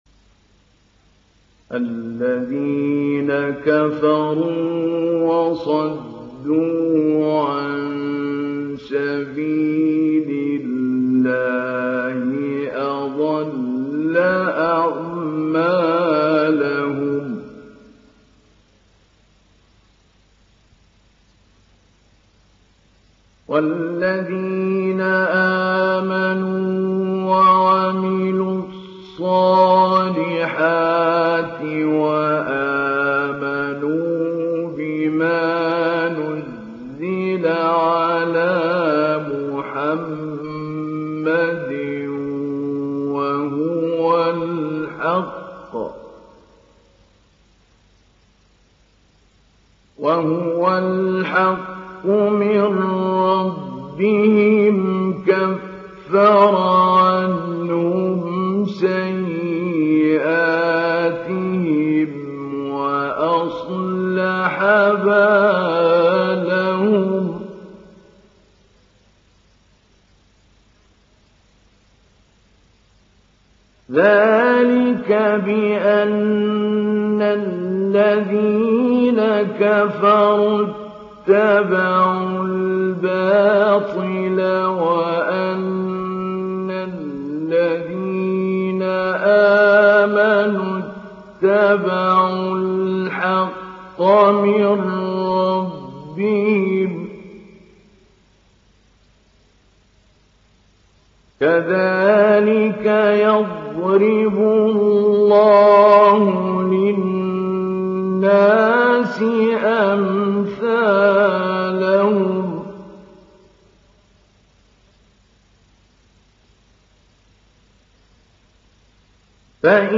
ডাউনলোড সূরা মুহাম্মাদ Mahmoud Ali Albanna Mujawwad